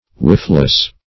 wifeless - definition of wifeless - synonyms, pronunciation, spelling from Free Dictionary
Wifeless \Wife"less\, a. Without a wife; unmarried.